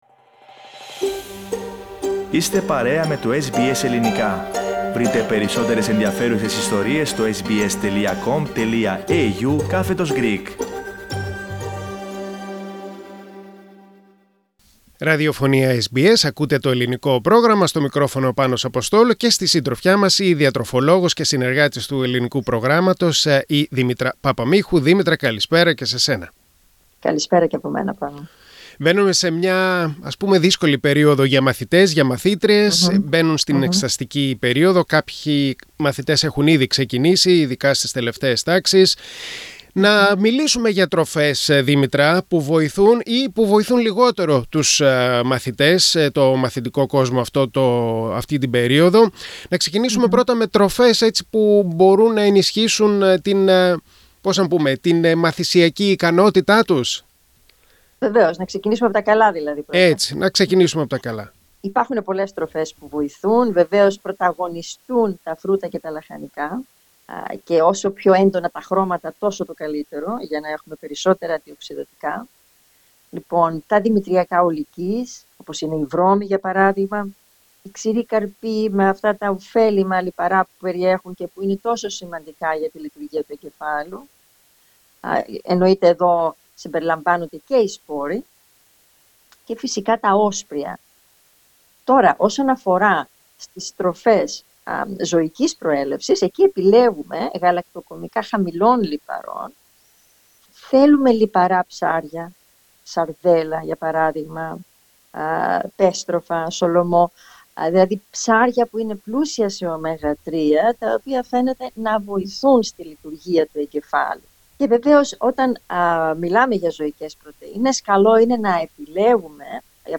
As part of the Weekly Nutrition Segment, nutritionist